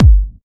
pcp_kick15.wav